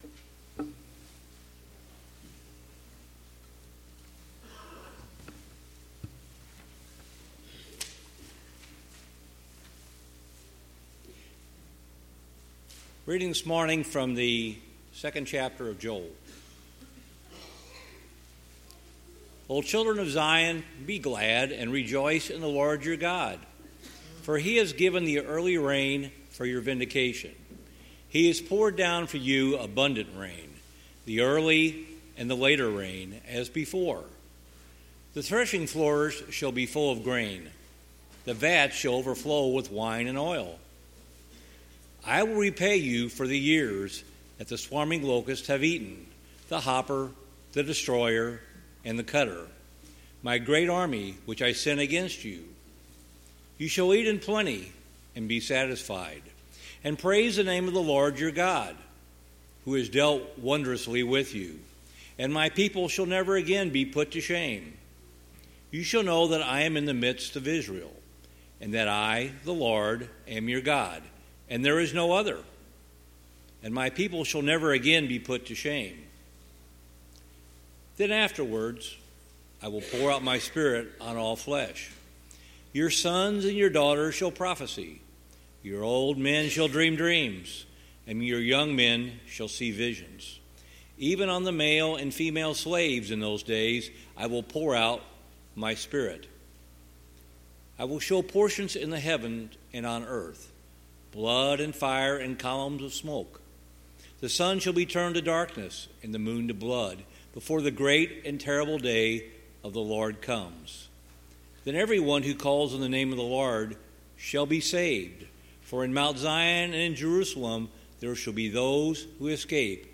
Passage: Joel 2:23-32 Service Type: Sunday Morning